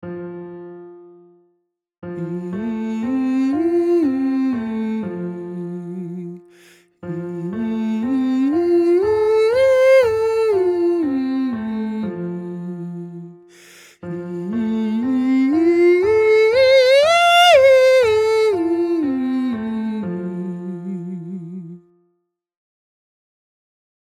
Stemtesten Bereik
met Stemtest 2 Man
Stemtest-2-Man.mp3